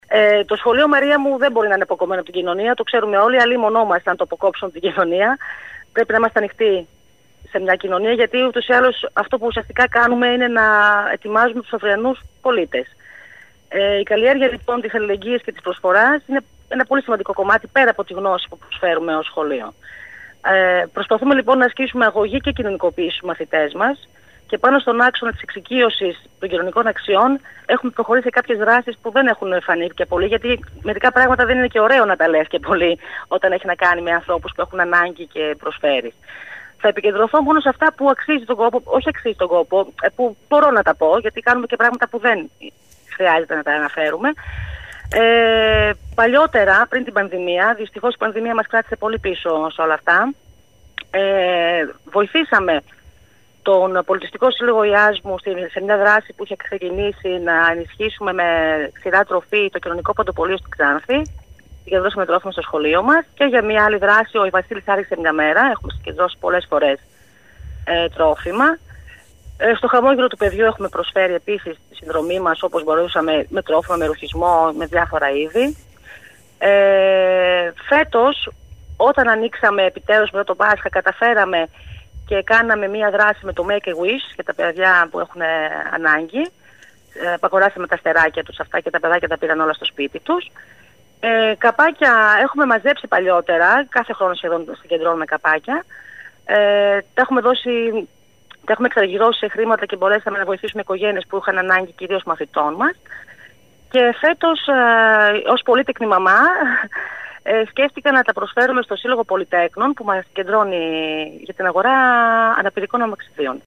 συνέντευξη -κείμενο